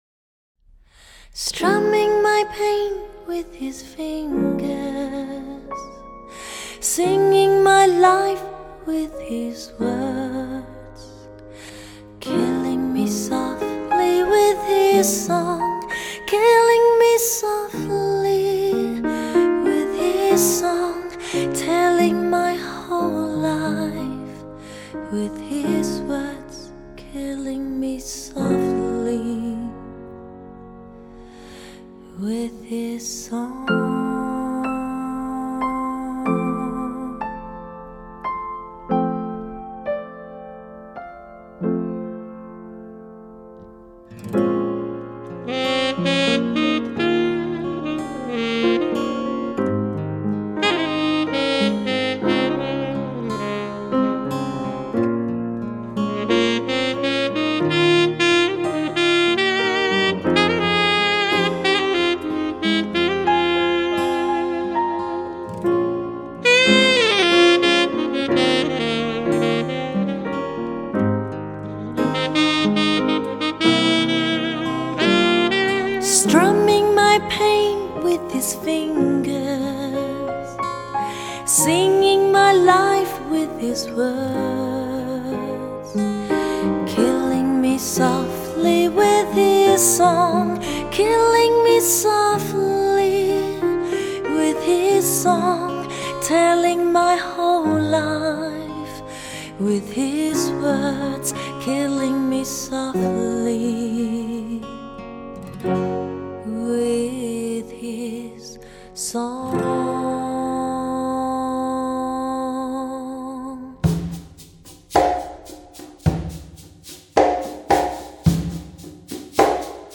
再配上本片天碟级的录音效果，造就那充满着清甜自然充满民歌风格的歌声